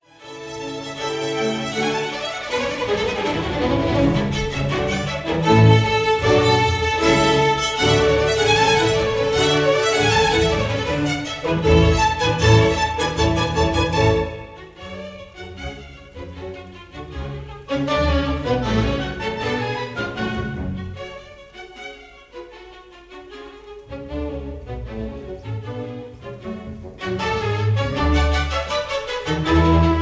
Presto - Adagio
Recorderd live at the 1st Aldeburgh
Jubilee Hall, Aldeburgh, Suffolk